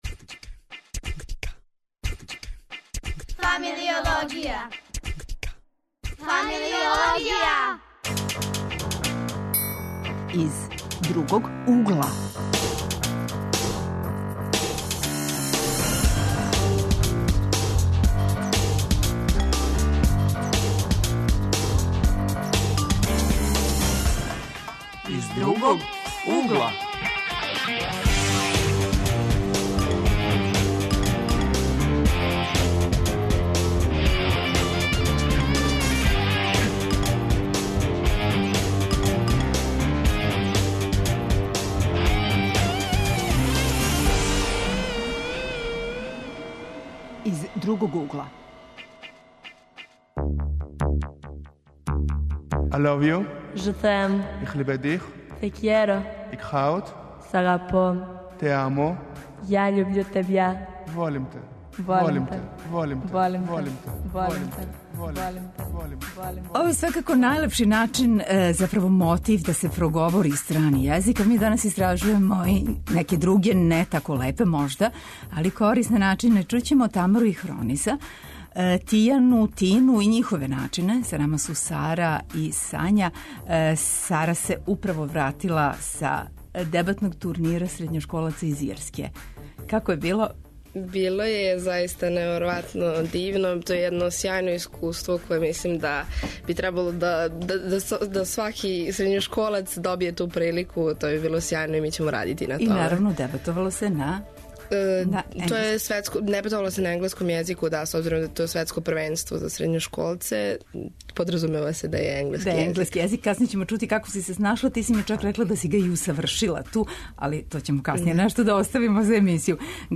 Гости су нам млади који говоре енглески, шпански, хебрејски, грчки, кинески...